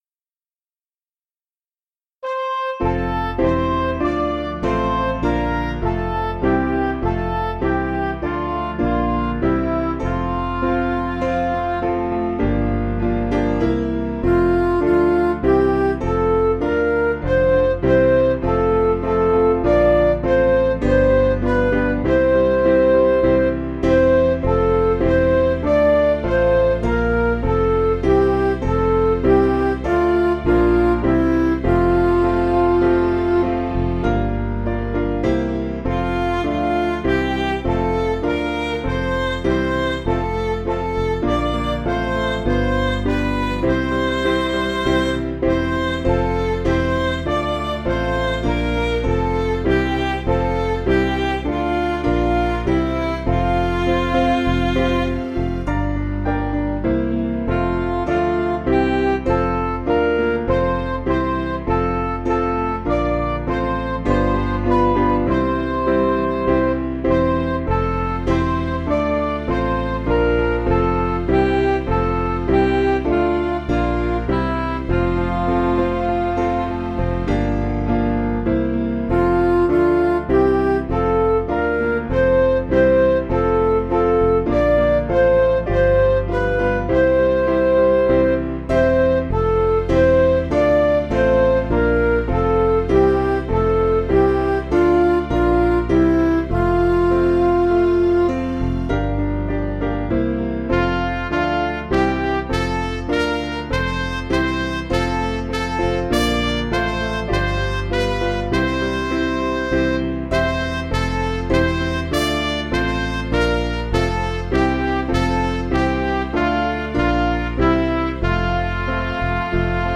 Midi
Piano & Instrumental